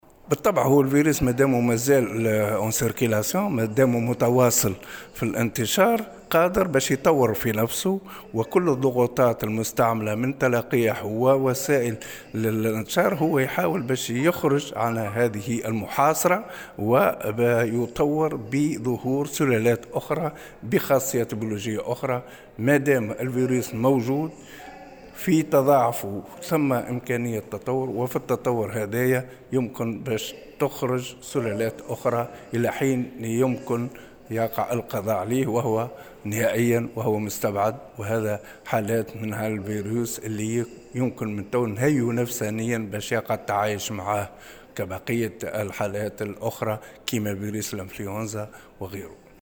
وأضاف في تصريح اليوم لمراسل "الجوهرة أف أم" على هامش المنتدى الرابع لجامعة المنستير، أنه من المستبعد القضاء عليه نهائيا وأنه يجب التعايش معه على غرار الفيروسات الأخرى (فيروس القريب).وأوضح أيضا أنه لابد من التقيّد بالإجراءات الوقائية من خلال الحرص على ارتداء الكمامات والحفاظ على التباعد الجسدي وتجنب التجمّعات والإقبال بكثافة على التلقيح.